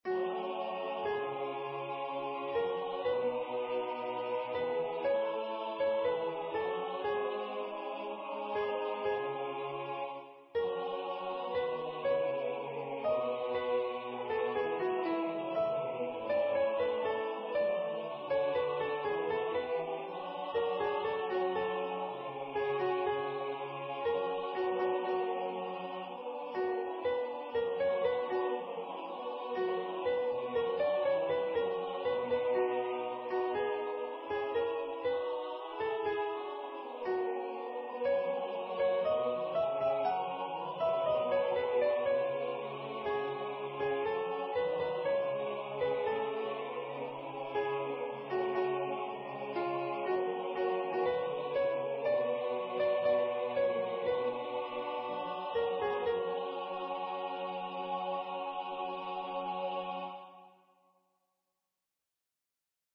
with Accompaniment
MP3 Practice Files: Soprano:
Number of voices: 4vv   Voicing: SATB
Genre: SacredAnthemBurial service